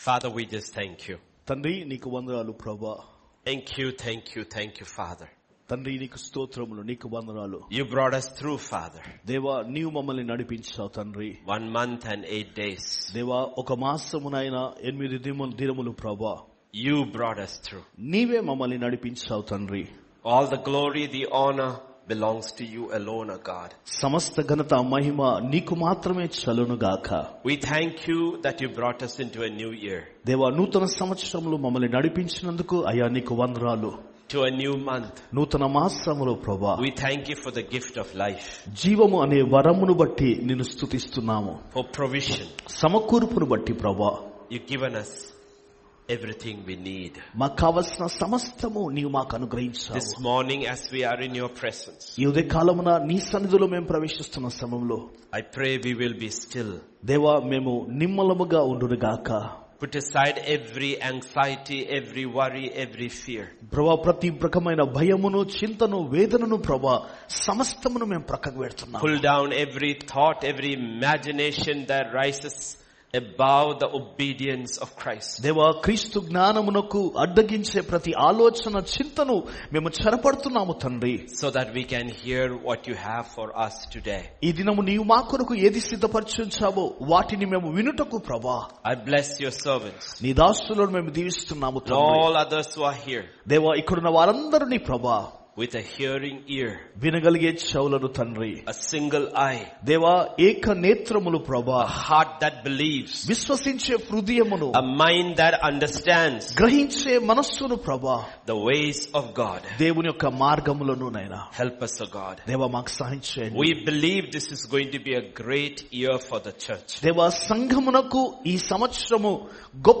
Sermon Preached on February 2025 Pastors Conference.